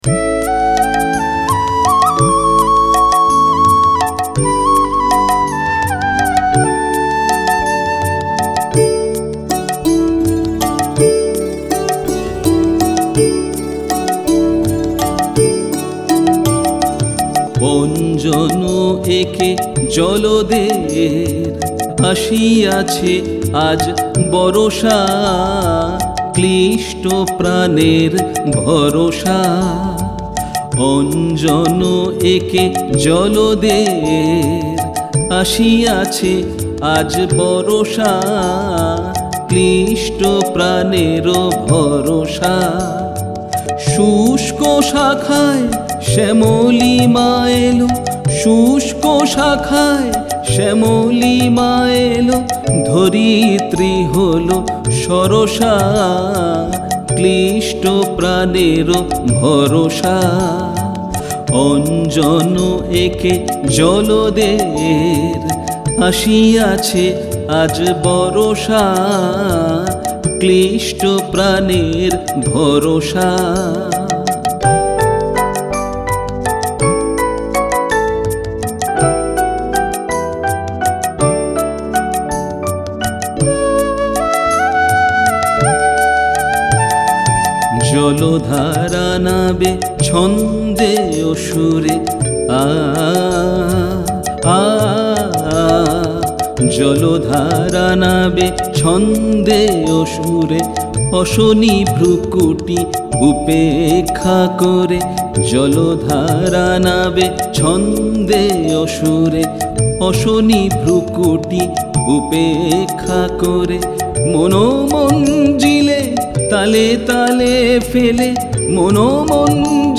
Music Dadra